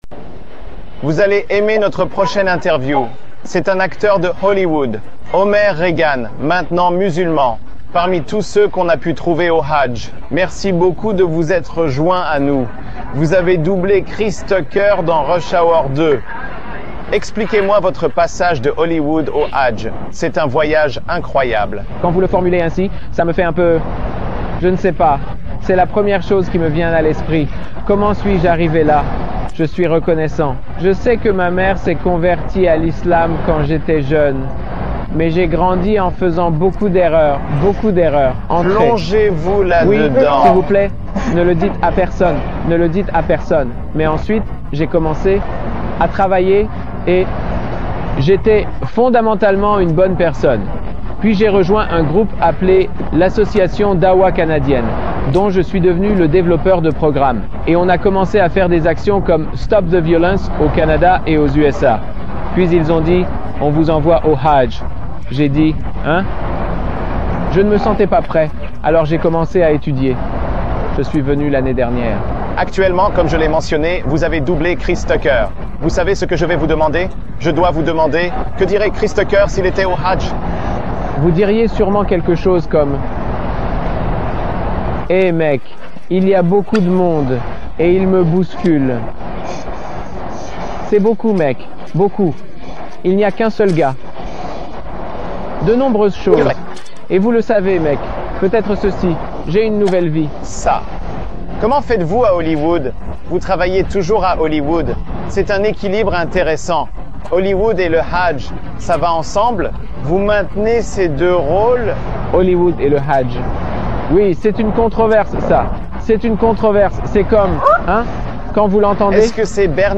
est interviewé par la chaîne internationale Al-Jazeera alors qu’il accomplit le 5e pilier de l’Islam.